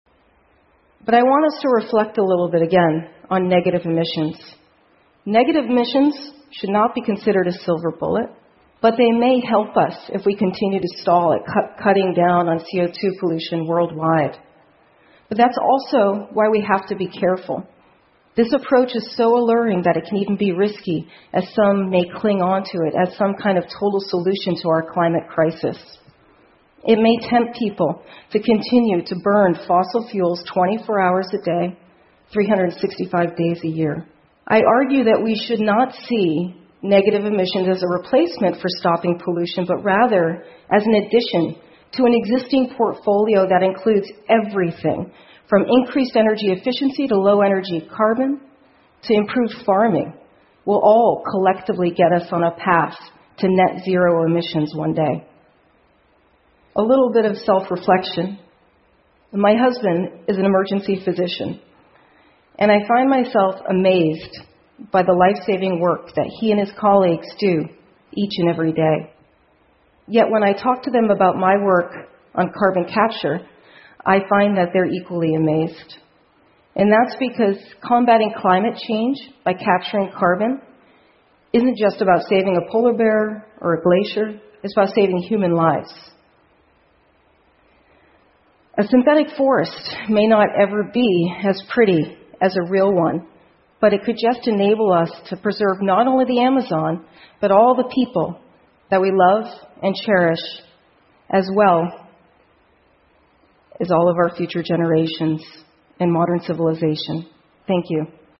TED演讲:从大气中移除二氧化碳的新方法() 听力文件下载—在线英语听力室